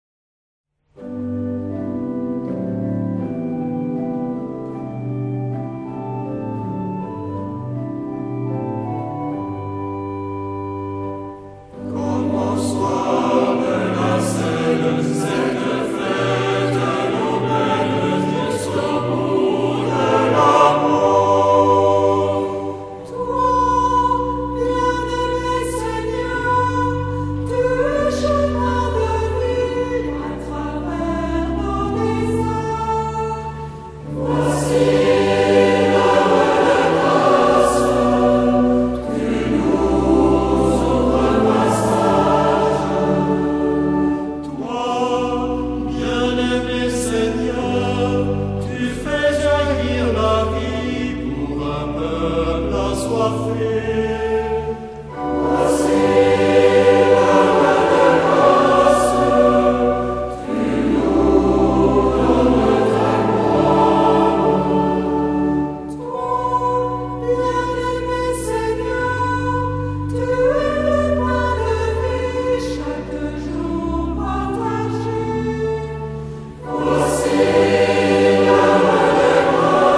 Genre-Stil-Form: geistlich ; Kirchenlied
Charakter des Stückes: ruhig
Chorgattung: SATB  (4-stimmiger gemischter Chor )
Instrumente: Orgel (1)
Tonart(en): G-Dur